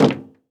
MetalSteps_05.wav